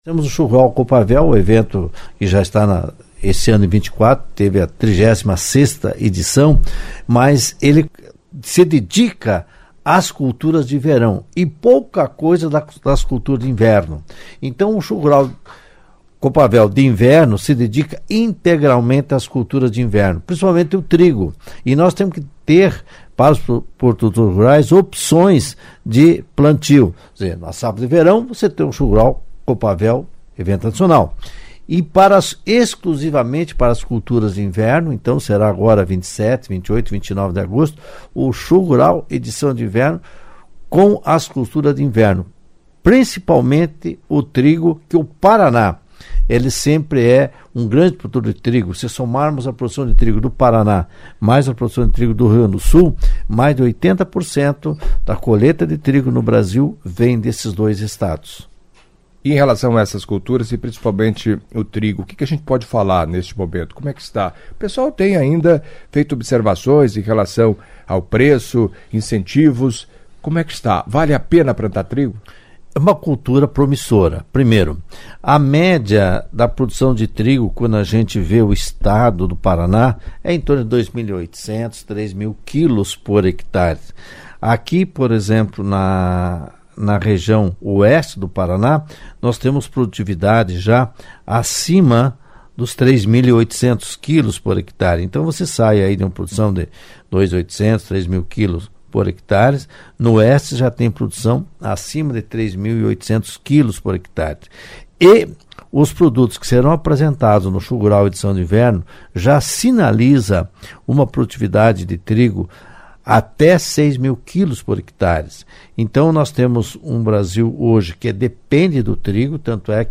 Em entrevista à CBN Cascavel nesta sexta-feira